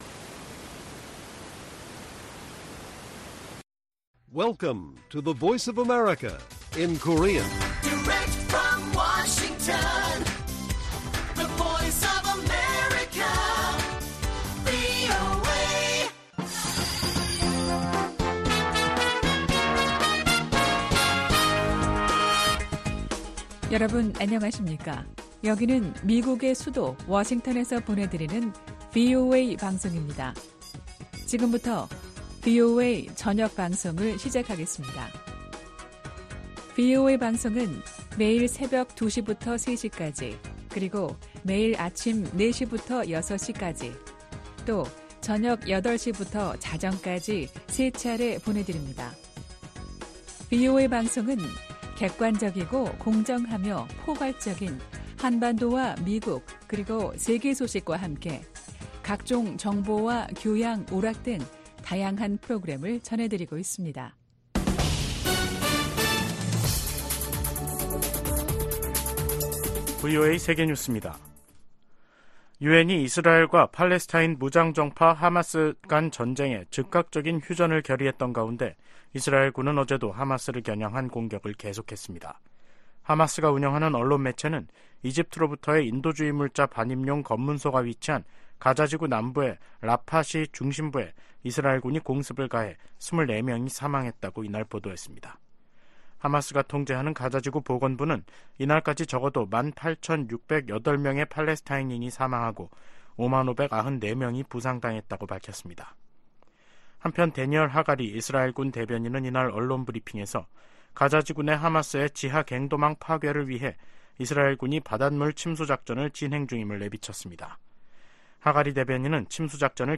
VOA 한국어 간판 뉴스 프로그램 '뉴스 투데이', 2023년 12월 14일 1부 방송입니다. 미국 정부가 북한 노동자들의 러시아 파견 정황에 대해 북러 협력 문제의 심각성을 지적했습니다. 미국 법무부 고위 당국자가 북한을 미국 안보와 경제적 이익에 대한 위협 가운데 하나로 지목했습니다. 네덜란드가 윤석열 한국 대통령의 국빈 방문을 맞아 북한의 미사일 발사를 비판하며 핵실험 자제를 촉구했습니다.